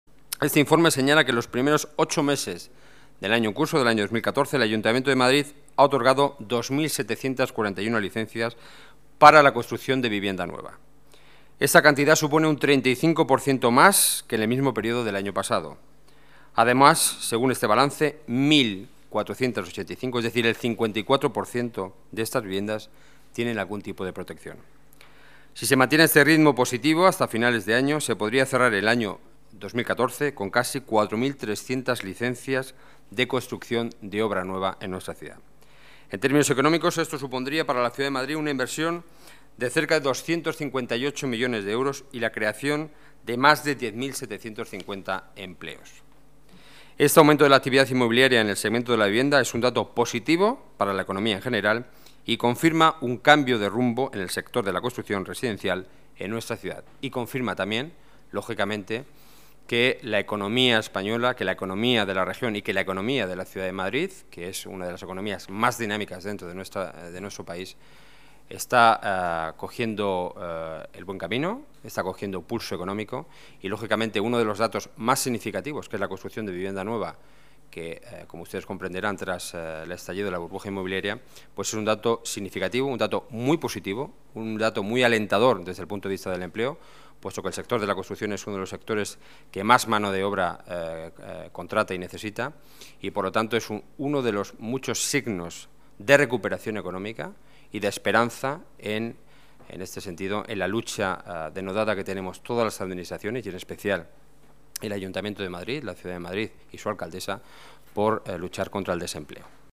Nueva ventana:Declaraciones del portavoz del Gobierno municipal, Enrique Núñez: aumenta un 35% la concesión de licencias para construir pisos